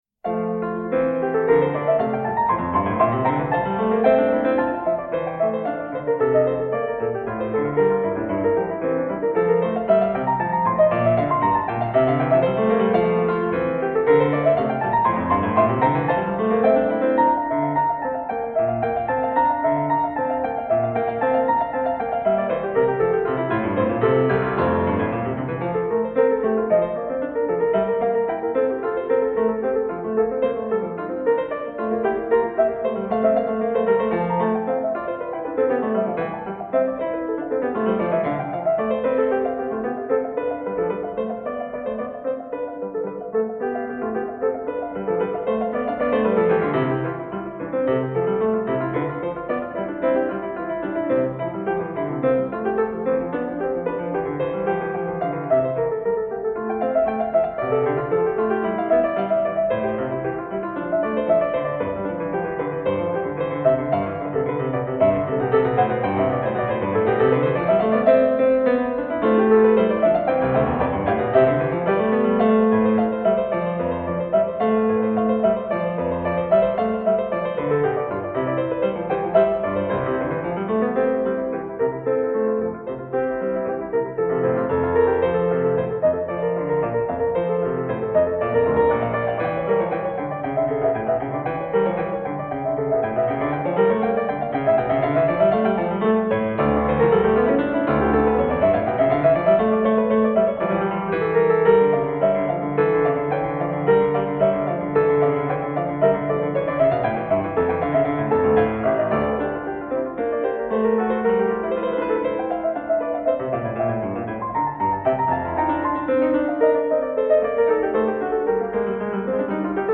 Solo piano recital.